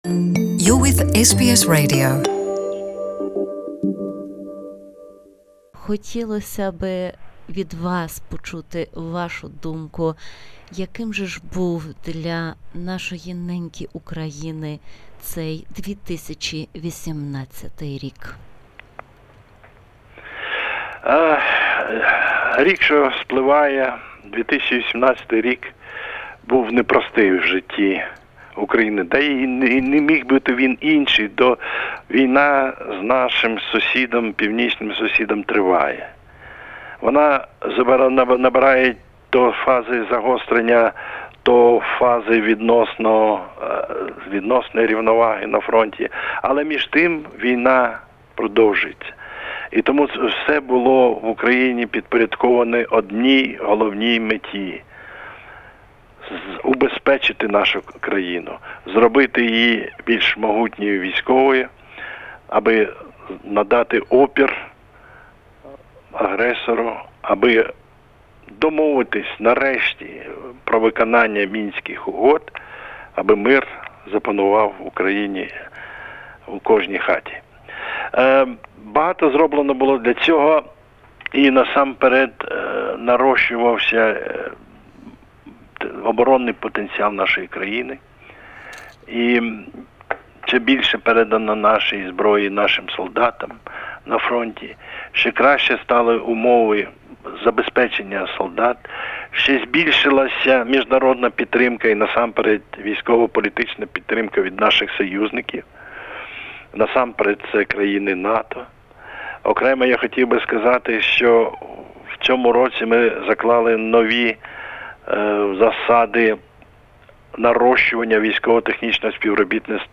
Interview with Ambassador of Ukraine in Australia Dr. Mykola Kulinich